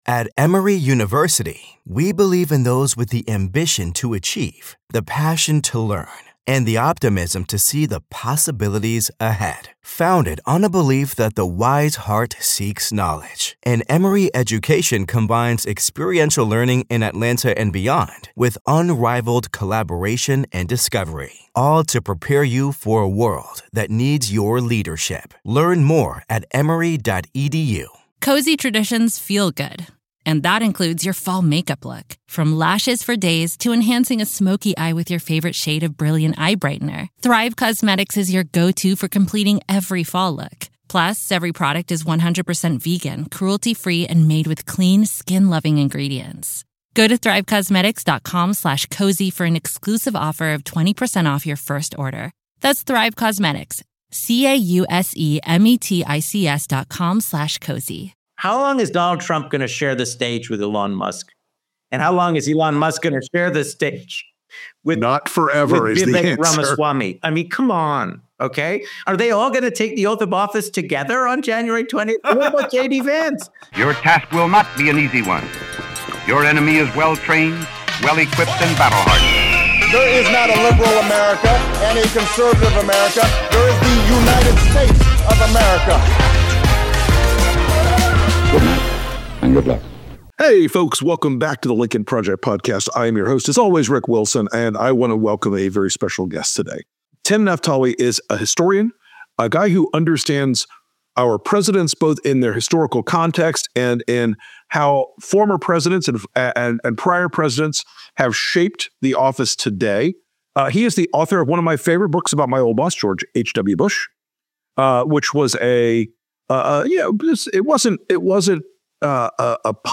Timothy Naftali, presidential historian for the Nixon Presidential Library, Columbia Scholar, and prolific writer/author, sits down with host Rick Wilson to discuss the possibility of an imperial presidency and what that means for the US on the world stage.